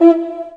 JJPercussion (351).wav